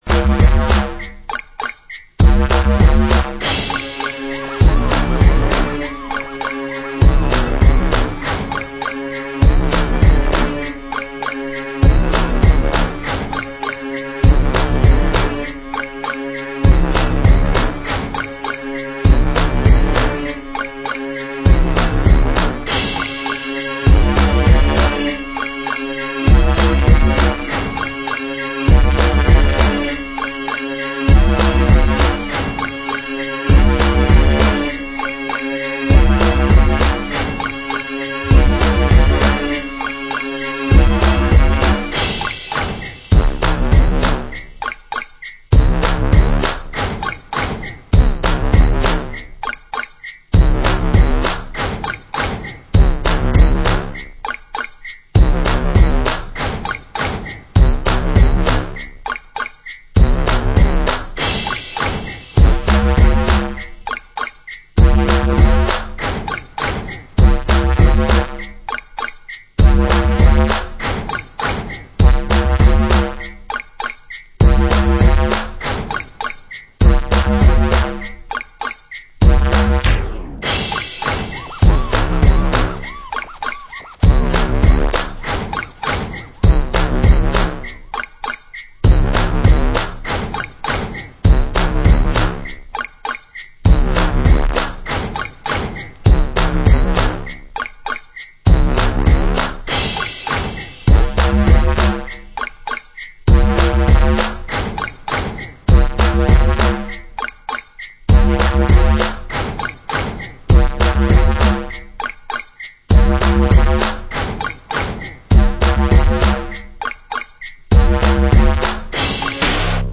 Genre: Grime